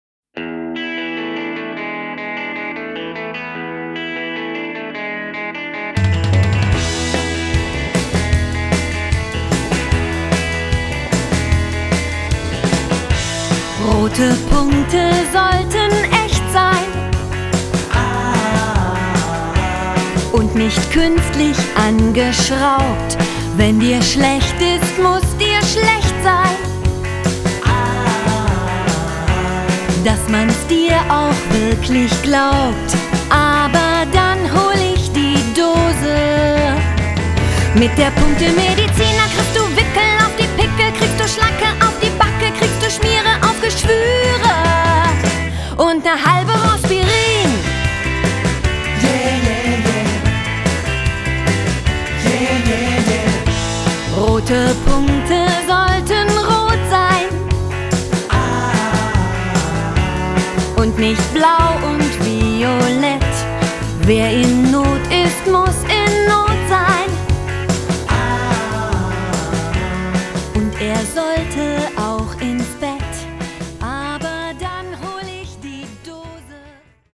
Eine CD mit allen Liedern und der Geschichte zum Anhören